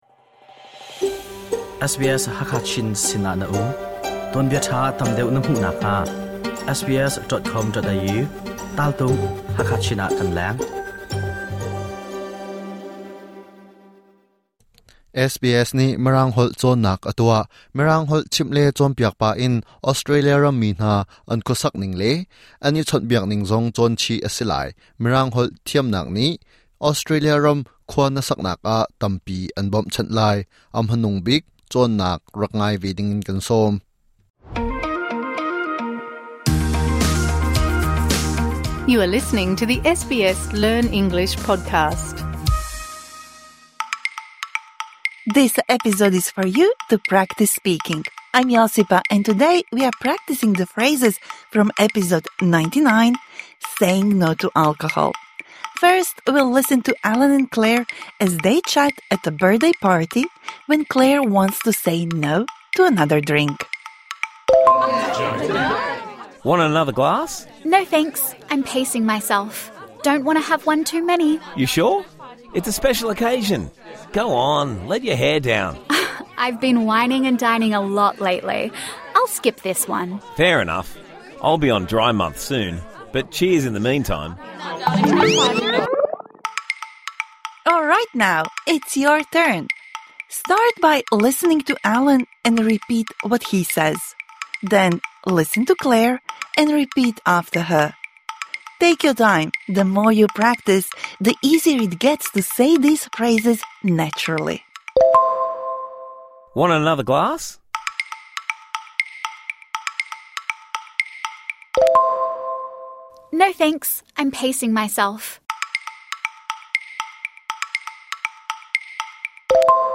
This bonus episode provides interactive speaking practice for the words and phrases you learnt in #99 Saying 'No' to alcohol.